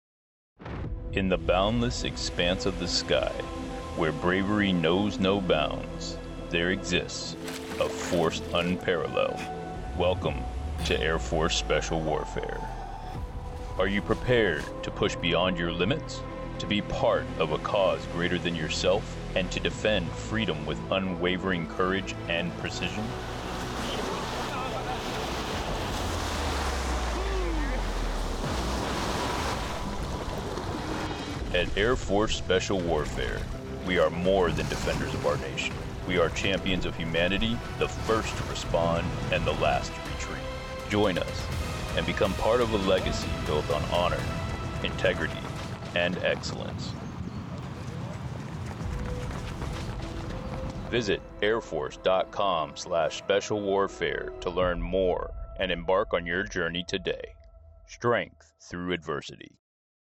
DMA Pacific - Hawaii Media Bureau